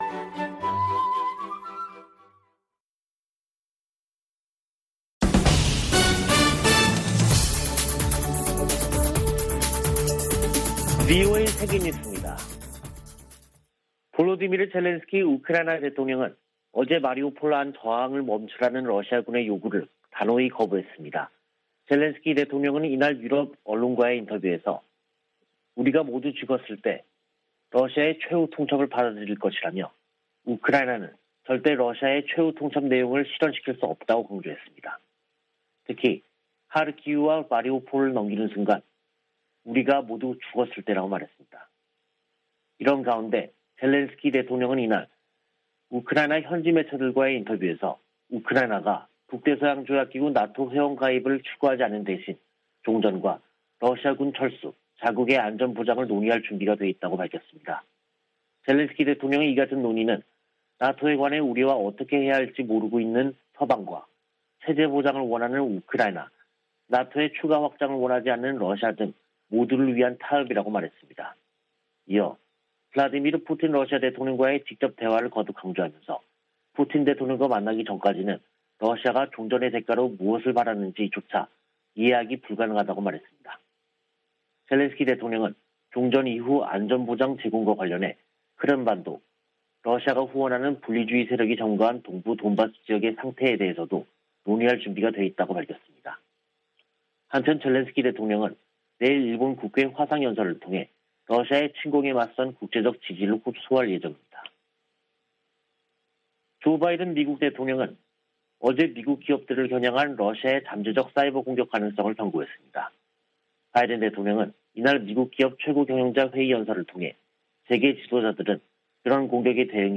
VOA 한국어 간판 뉴스 프로그램 '뉴스 투데이', 2022년 3월 22일 2부 방송입니다. 북한이 연일 미한 연합훈련을 비난하는데 대해, 이는 동맹의 준비태세를 보장하는 주요 방법이라고 미 국방부가 밝혔습니다. 윤석열 한국 대통령 당선인이 북한의 최근 서해상 방사포 발사를 9.19 남북군사합의 위반이라고 말한데 대해 한국 국방부가 합의 위반은 아니라고 밝혀 논란을 빚고 있습니다. 미 의회에서 북한의 사이버 위협에 대응하기 위한 입법 움직임이 활발합니다.